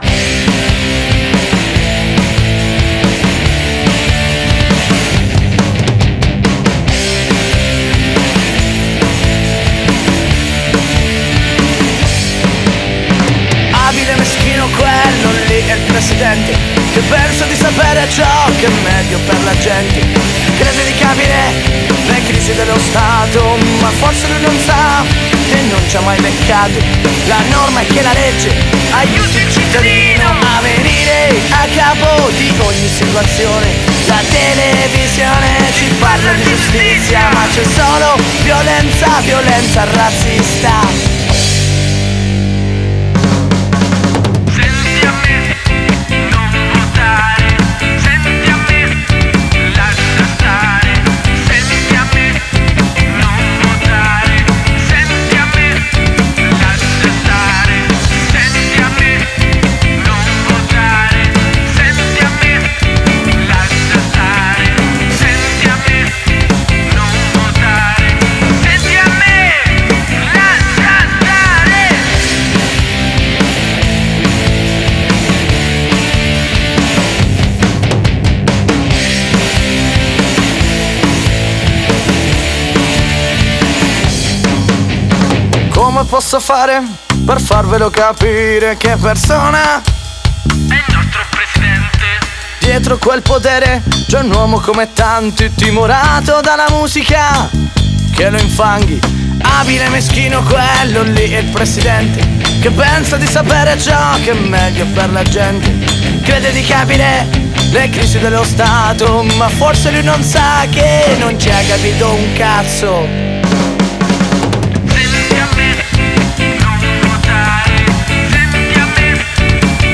punk hardcore